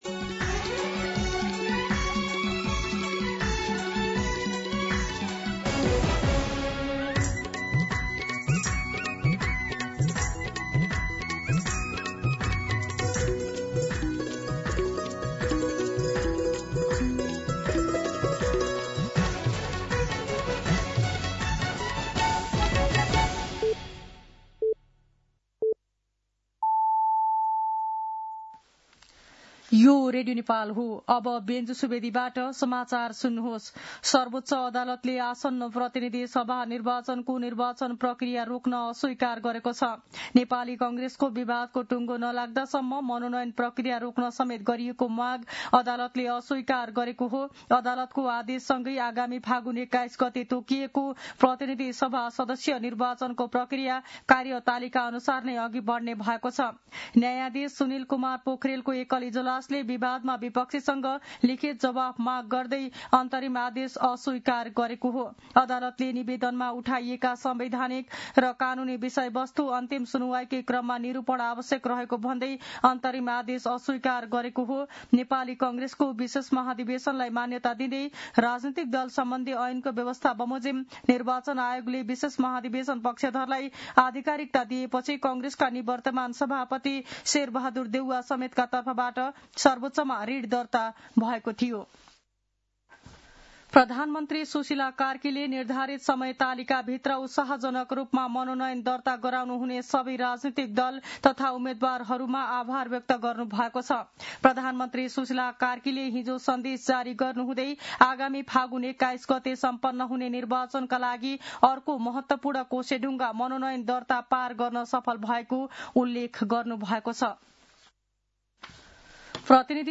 मध्यान्ह १२ बजेको नेपाली समाचार : ७ माघ , २०८२
12pm-Nepali-News-1.mp3